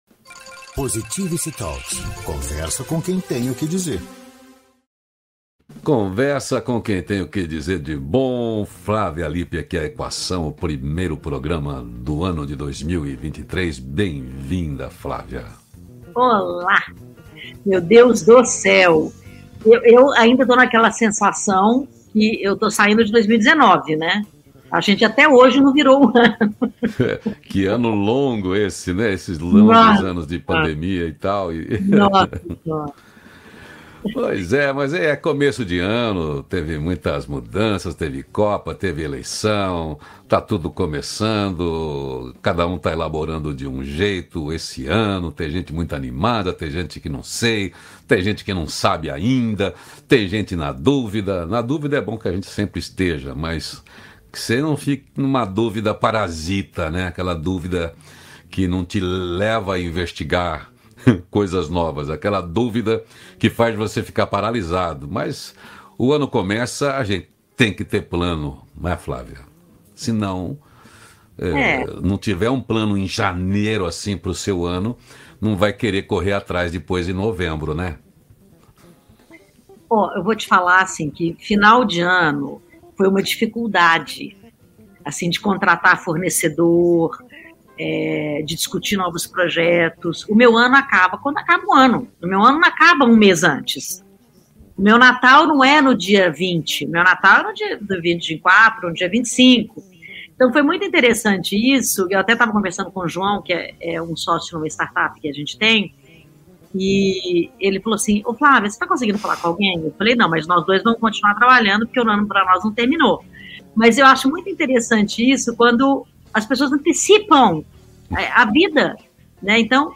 Como organizar o seu ano de 2023. Ouça este diálogo